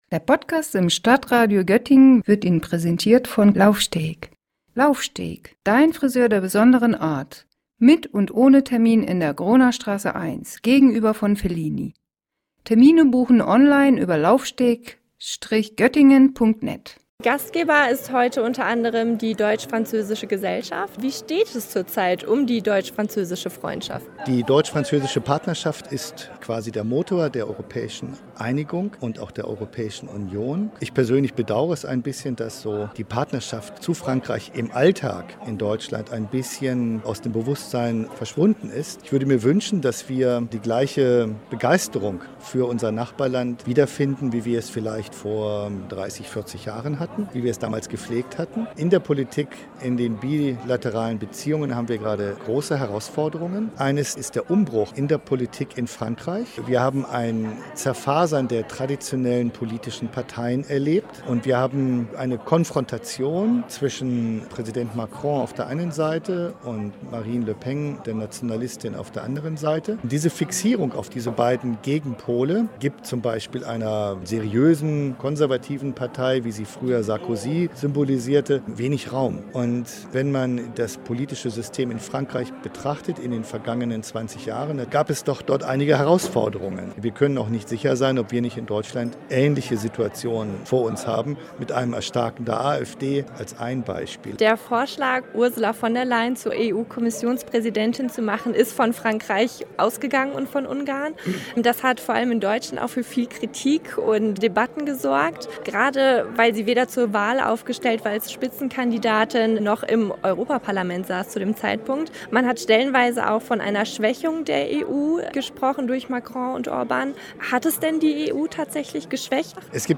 Am Freitag hat im Göttinger Gemeindesaal der Sankt Johanniskirche der jährliche Europaempfang stattgefunden.